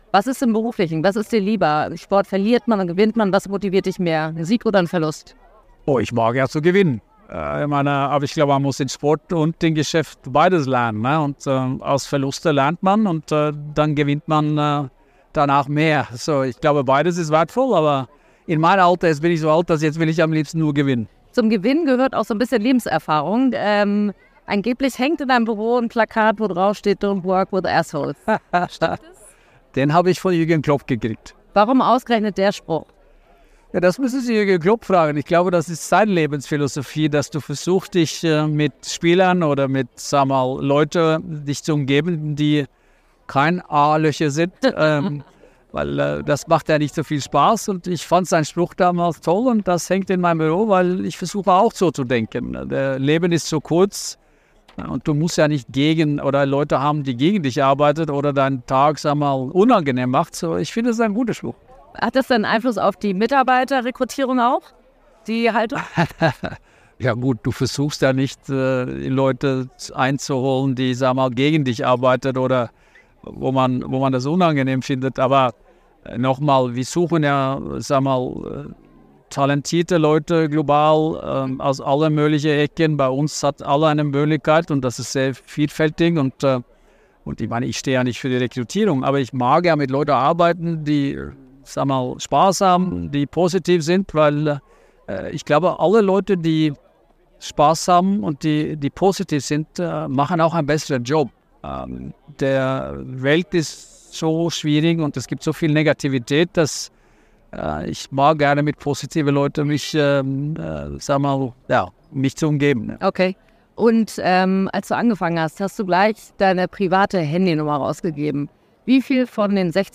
Konzern-Chef Bjørn Gulden (59) spricht im großen BILD-Interview über seine Führungsphilosophie, den Umgang mit Leistungsdruck und warum er seine Handynummer an alle Mitarbeiter herausgegeben hat.